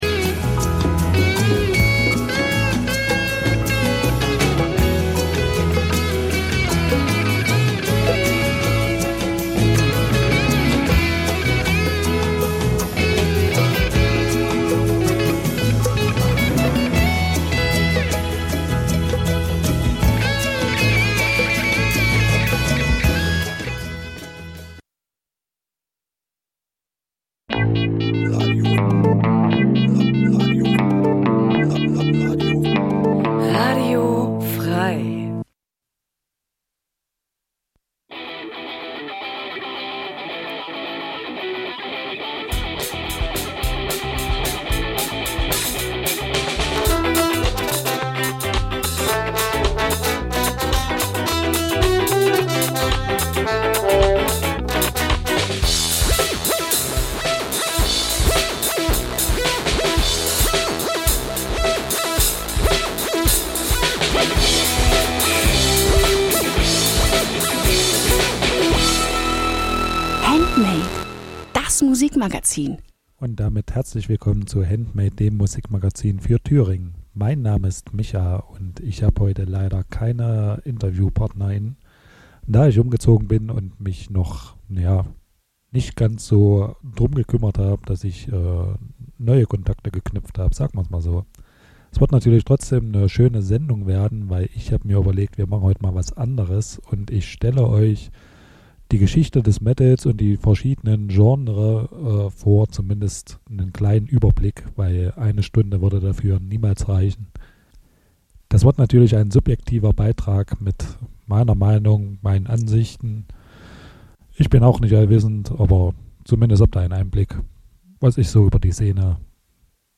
Jeden Donnerstag stellen wir euch regionale Musik vor und scheren uns dabei nicht um Genregrenzen.
Wir laden Bands live ins Studio von Radio F.R.E.I. ein, treffen sie bei Homesessions oder auf Festivals.
Regionale Musik Dein Browser kann kein HTML5-Audio.